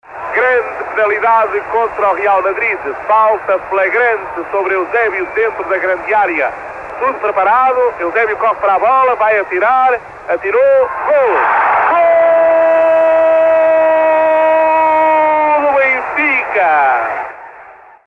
Explicação O SL Benfica volta a conquistar a Taça dos Clubes Campeões Europeus em 1961/1962, depois de vencer o troféu na época anterior. Artur Agostinho faz o relato da partida, que os encarnados vencem por 5 a 3, com golos de José Águas, Cavém, Mário Coluna e um bis de Eusébio.